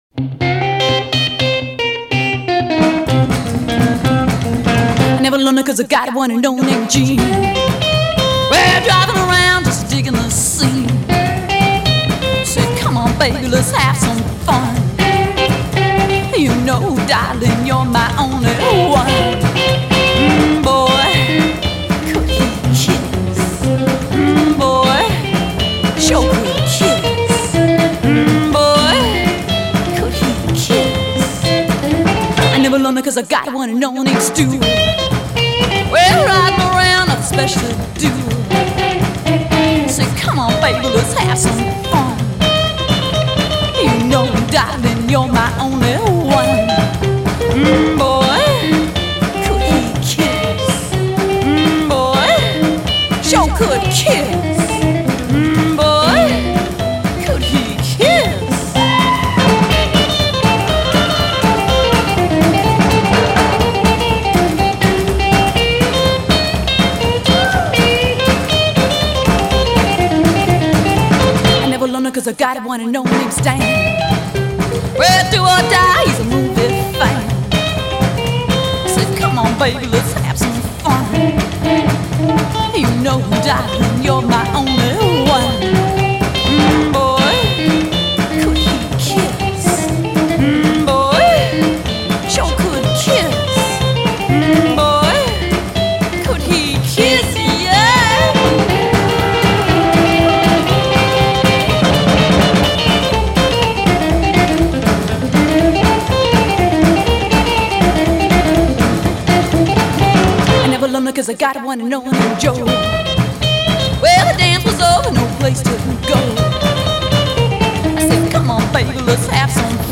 Жанр: Rock
Стиль: Rockabilly